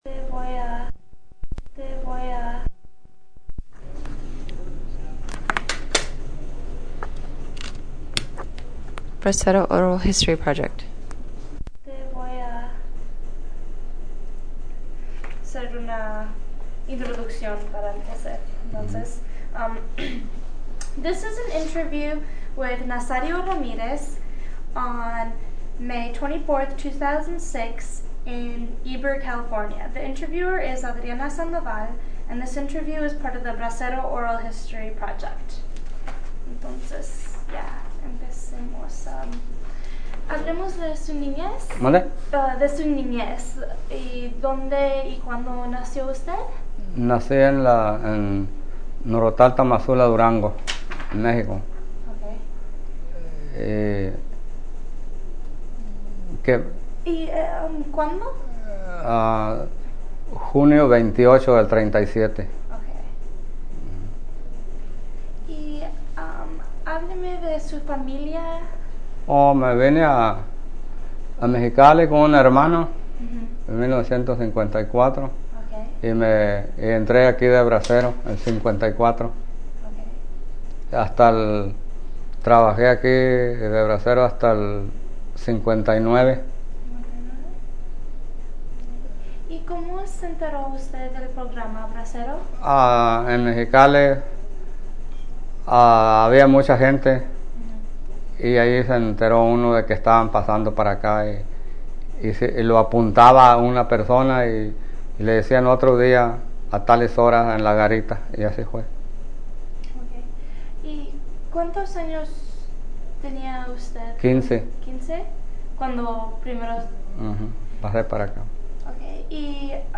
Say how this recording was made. Original Format Mini disc Location Heber, CA